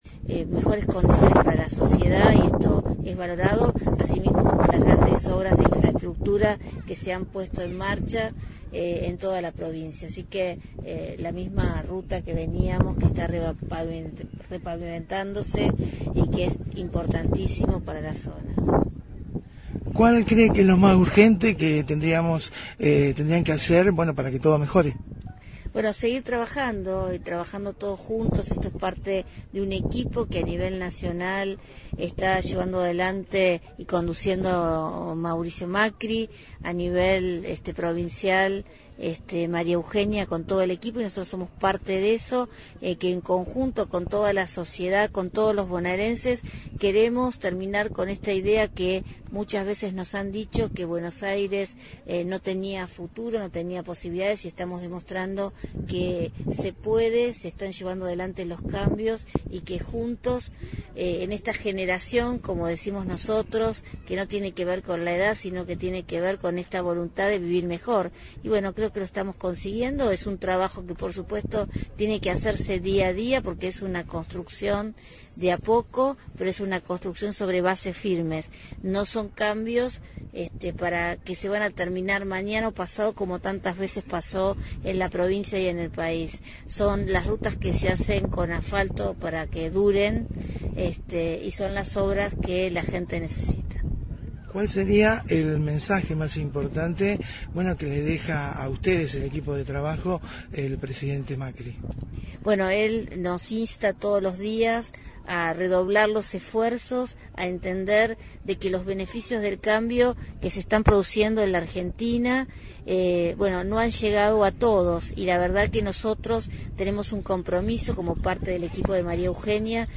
VISIÓN EMPRESARIA en una nota exclusiva con Graciela.